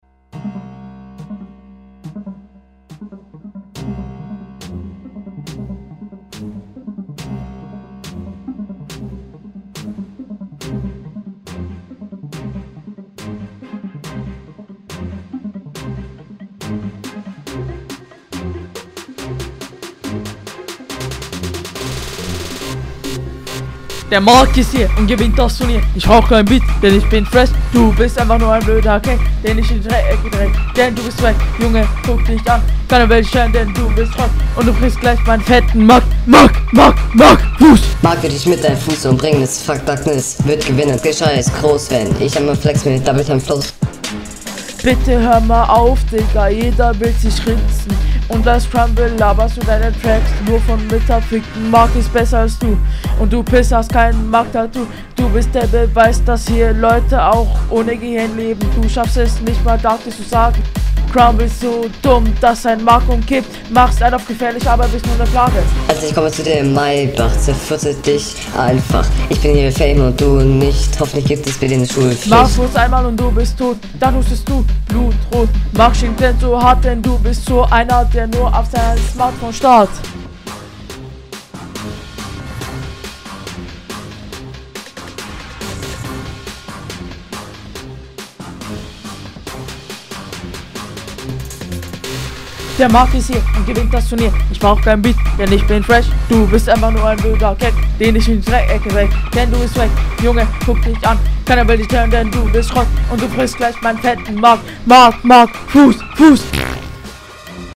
Flow: ist halt ganz ok irgentwo aber ist noch sehr unroutiniert Text: ich finde es …